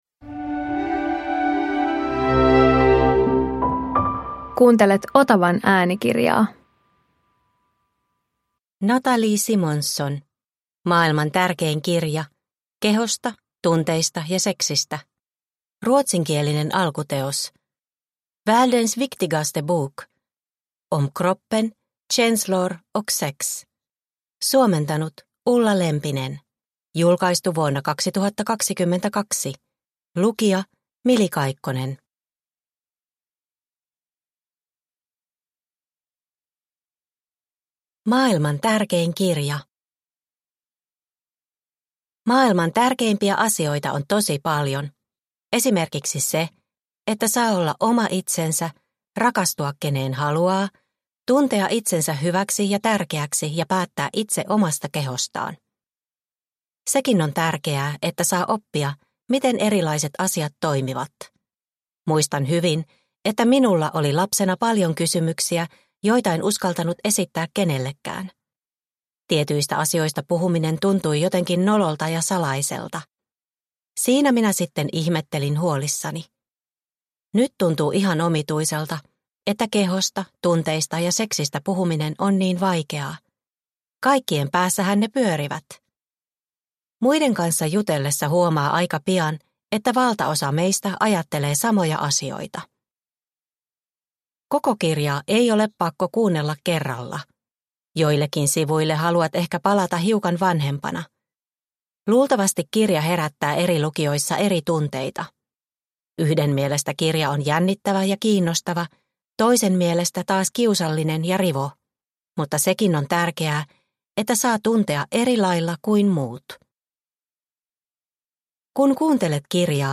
Maailman tärkein kirja – Ljudbok – Laddas ner